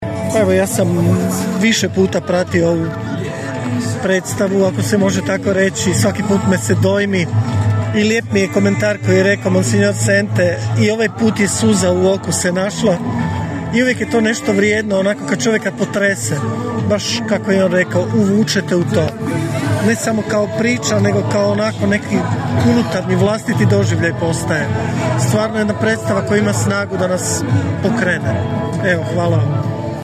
U publici je bio i đakon